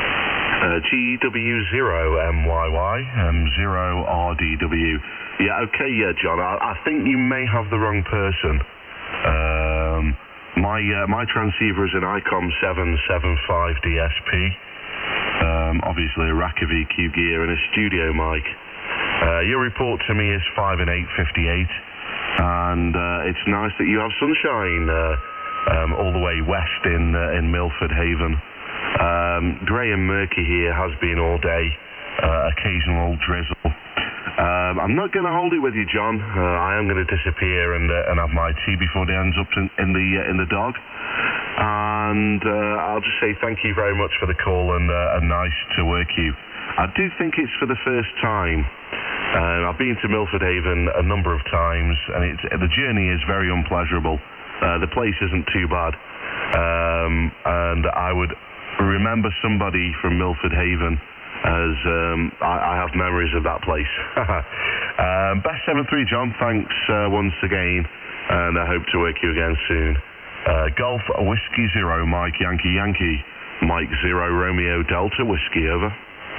Recorded using Kenwood TS-870S @ 0 - 3400Hz
To appreciate the full fidelity of the enhanced frequency response.